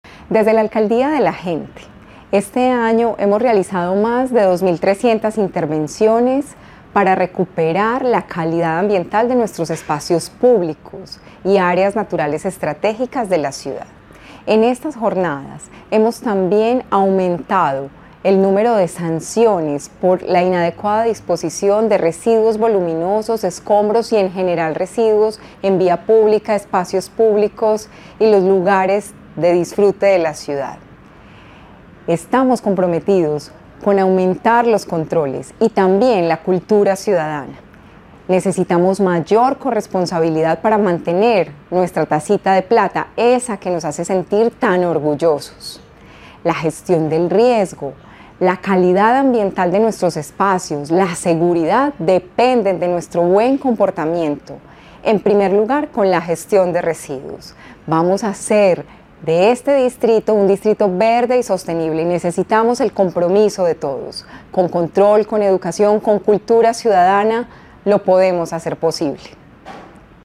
Declaraciones de la secretaria de Medio Ambiente, Marcela Ruiz El Distrito reforzó este año las estrategias de control para enfrentar la disposición inadecuada de basuras y escombros en el espacio público.
Declaraciones-de-la-secretaria-de-Medio-Ambiente-Marcela-Ruiz-1.mp3